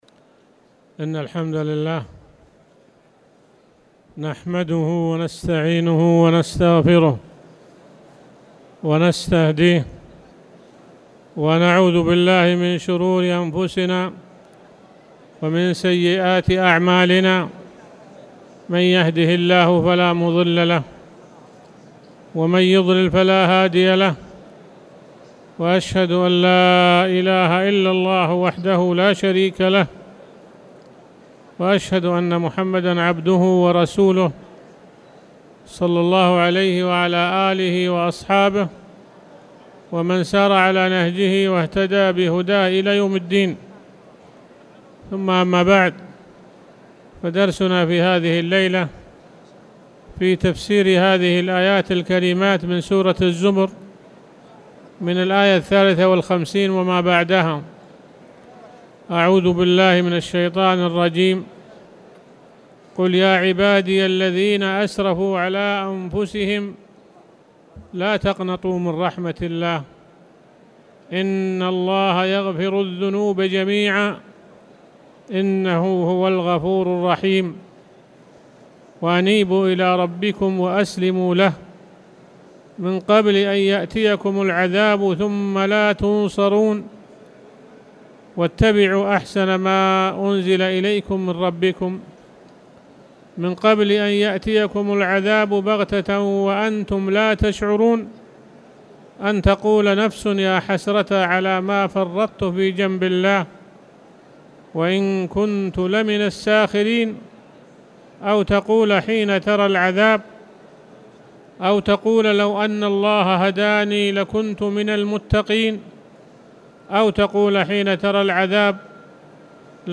تاريخ النشر ١٨ ربيع الثاني ١٤٣٨ هـ المكان: المسجد الحرام الشيخ